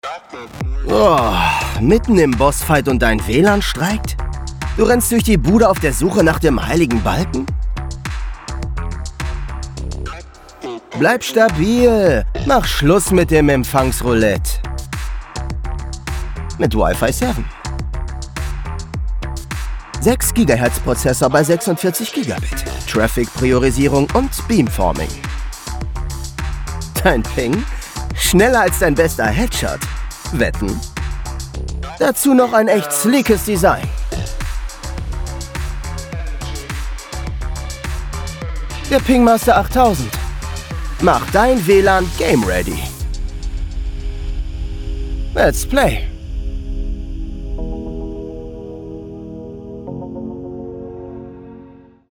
Young Adult
Acoustics: Vocal booth including Caruso Iso Bond 10cm, Basotect for acoustic quality.